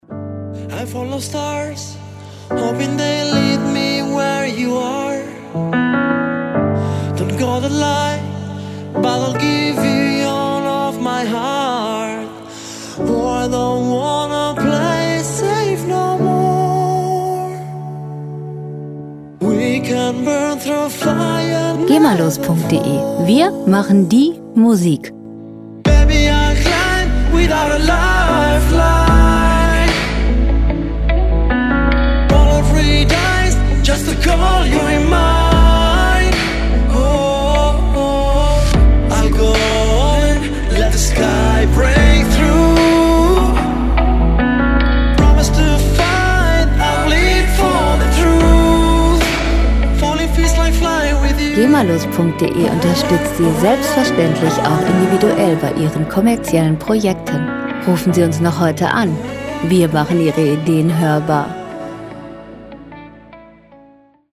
Lounge Musik - Romanzen
Musikstil: Pop Ballade
Tempo: 79,5 bpm
Tonart: D-Dur
Charakter: melodisch, melancholisch
Instrumentierung: Synthesizer, E-Gitarre, Piano, Pop Duett